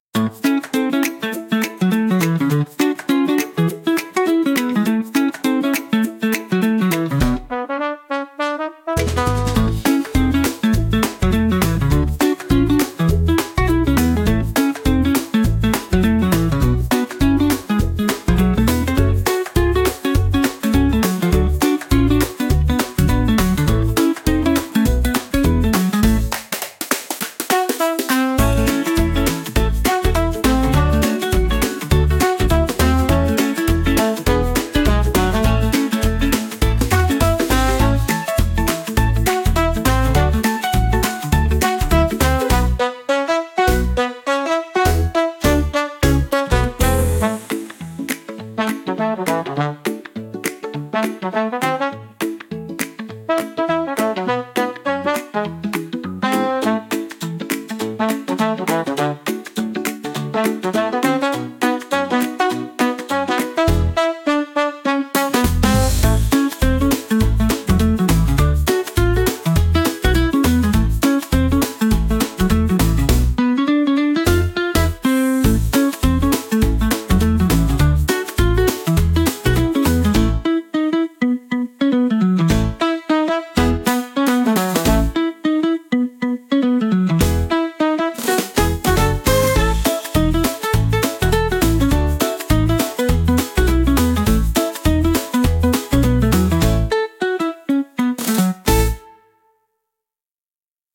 Children
Happy, Groovy, Energetic, Playful
103 BPM